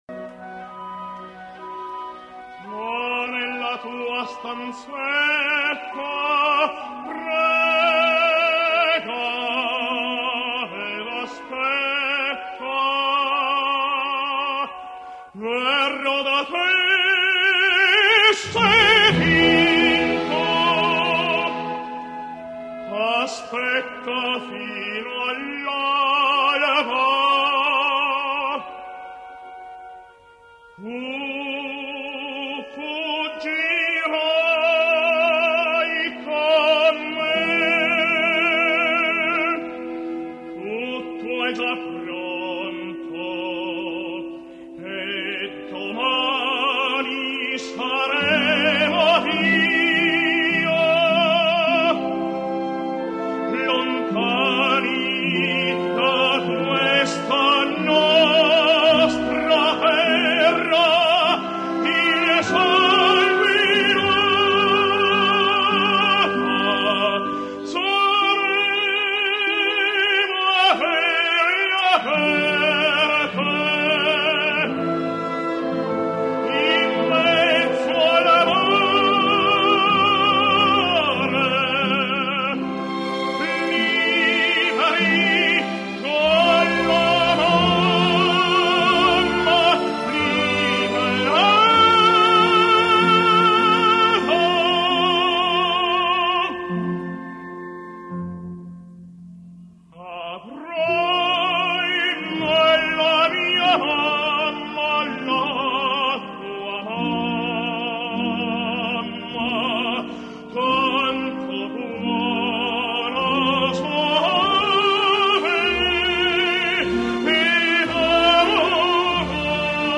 Revolutionsoper — {historischer Verismo}
Il principino & Mariella
Mariella [Sopran]